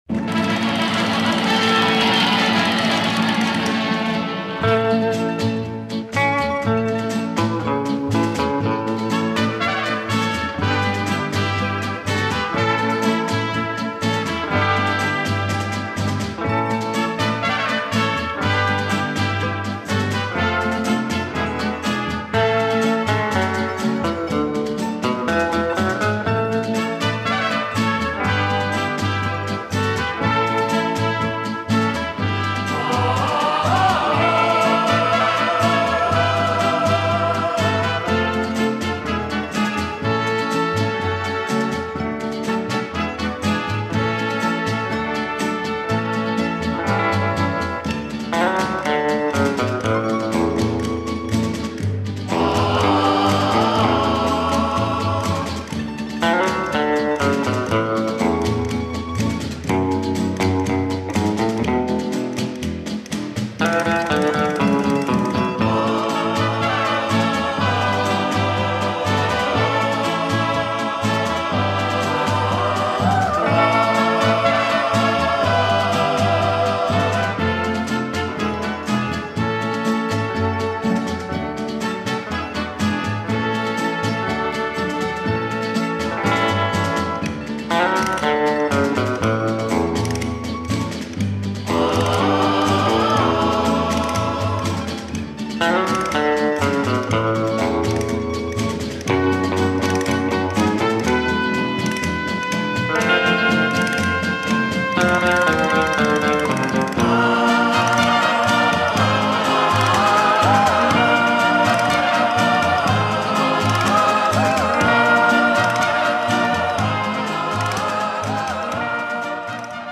трубачом и руководителем оркестра